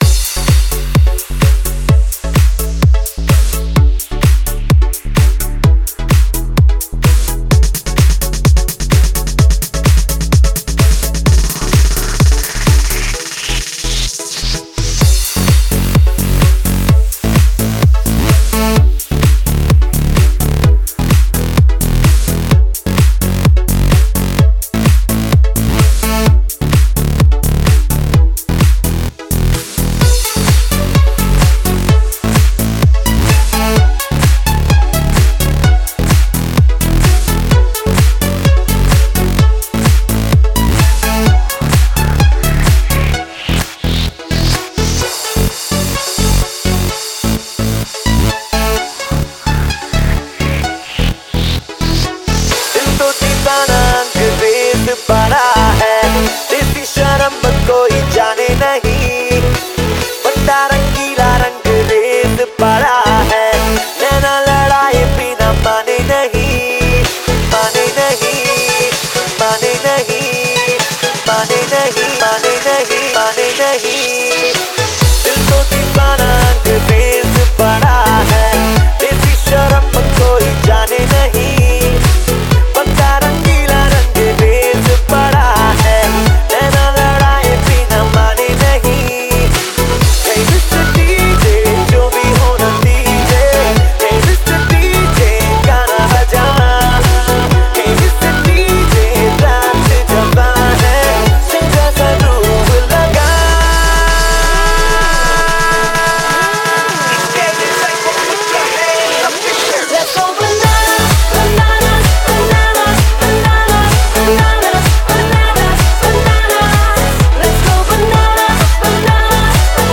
HomeMp3 Audio Songs > Others > Latest DJ-Mixes (August 2013)